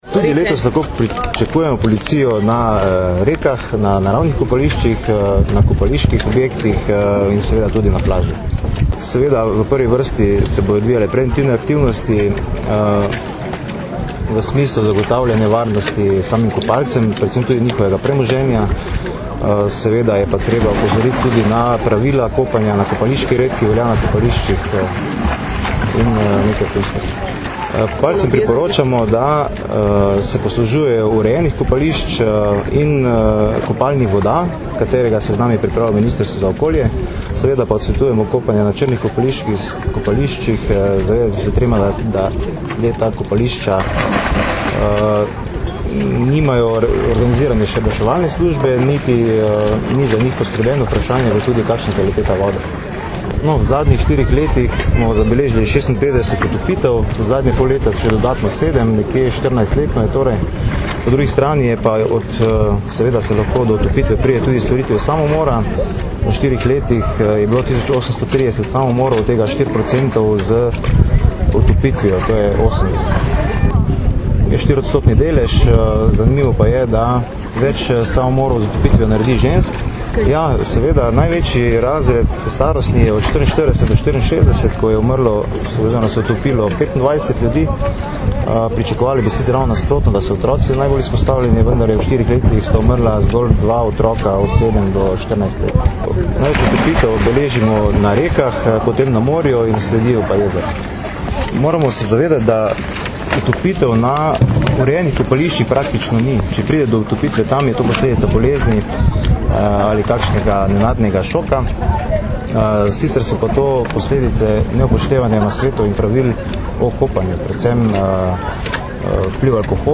Ob tej priložnosti smo danes, 22. julija 2008, v regatnem centru Zaka na Bledu pokazali tudi delopolicistov potapljačeviz Specialne enote.